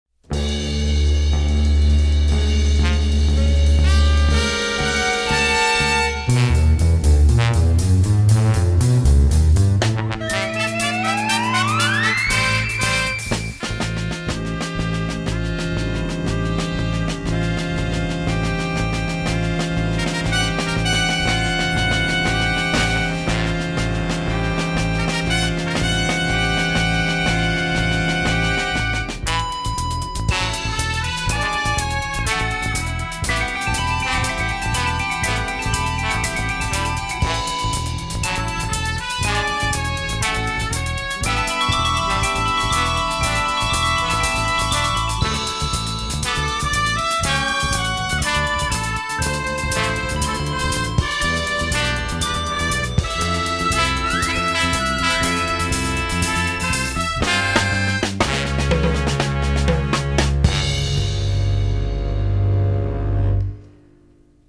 monaural sound from master tapes